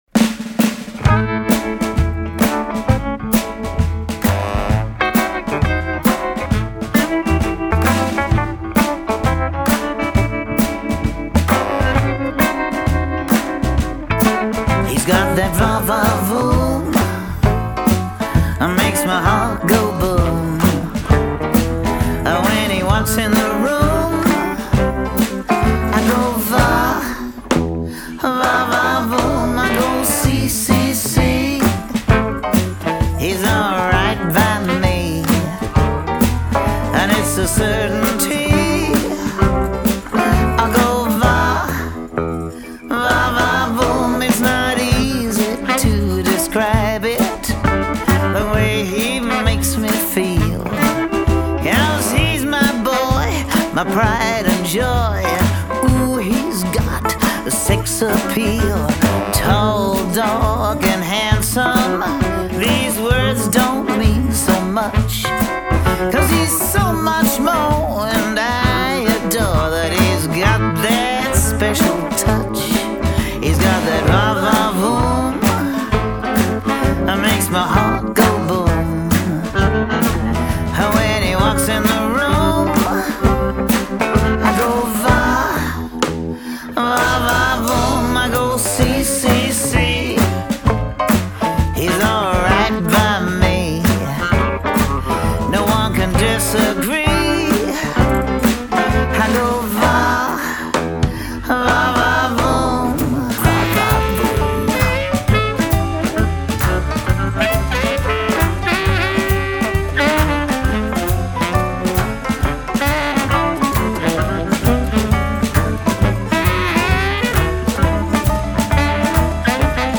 Un disque de swing blues par une chanteuse britannique